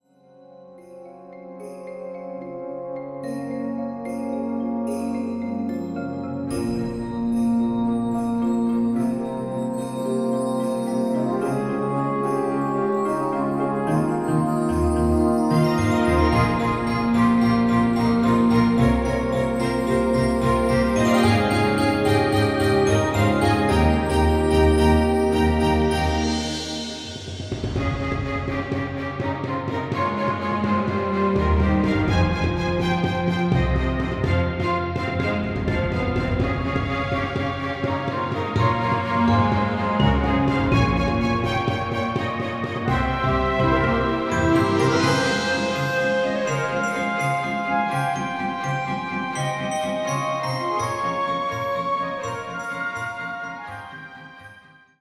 Division: 2024 Collegiate Division, Full Orchestra